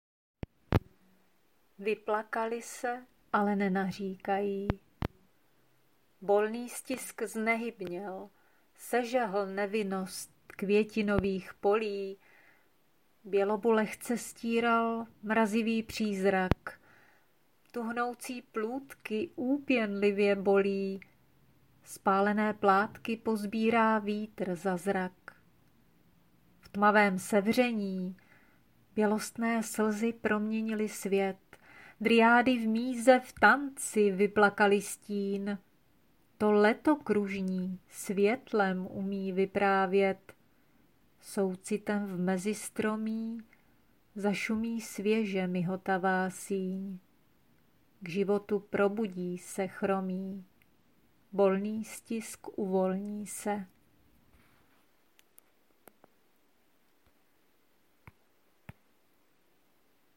Básně » Příroda
Máš krásný a velmi příjemný hlas i přednes